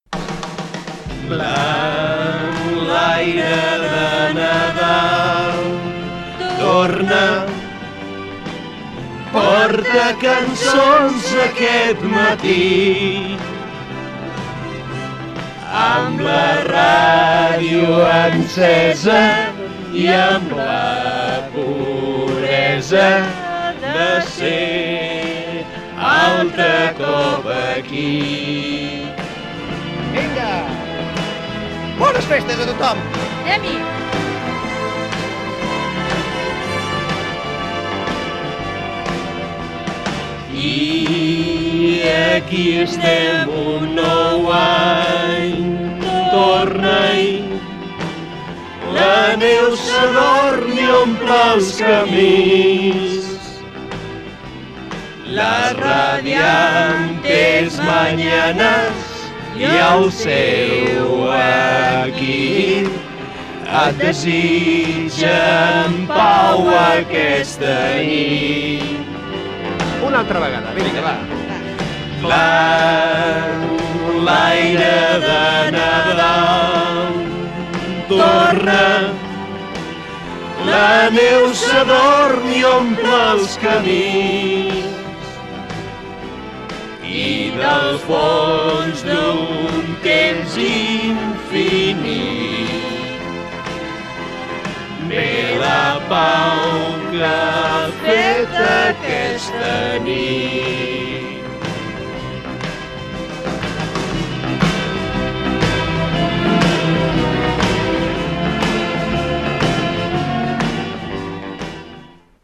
Nadala del programa